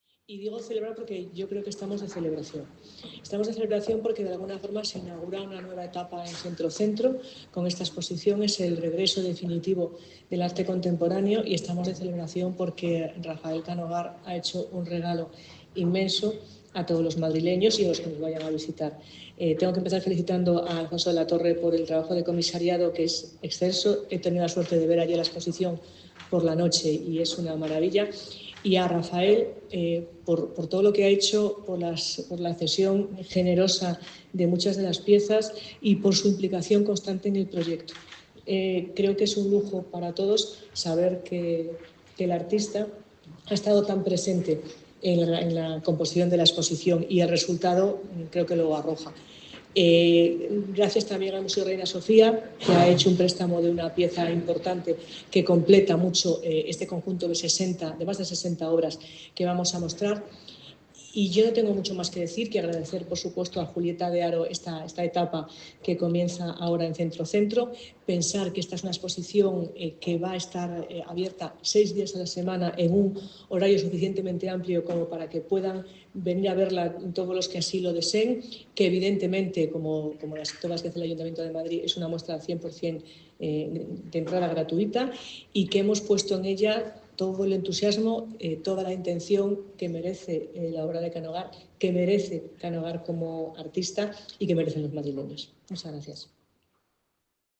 Nueva ventana:La delegada de Cultura, Turismo y Deporte, Marta Rivera de la Cruz, durante la presentación de la exposición